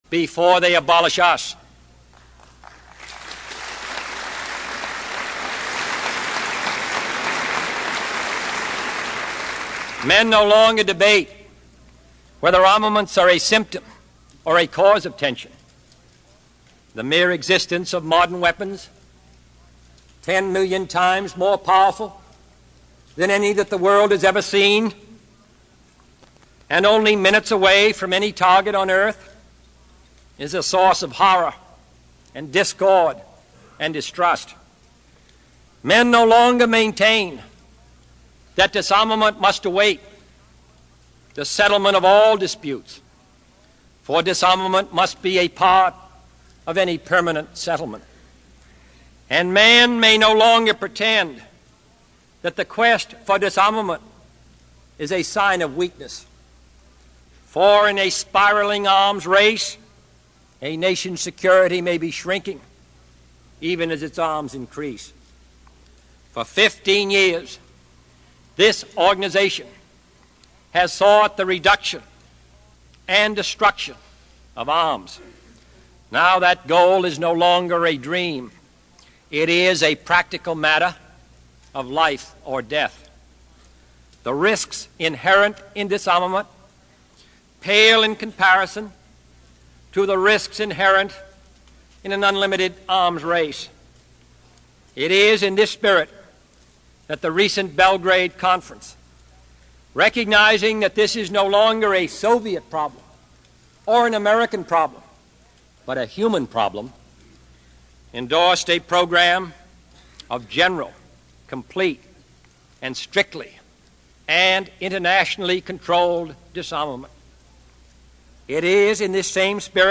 Tags: John F. Kennedy John F. Kennedy Address United Nations John F. Kennedy speech President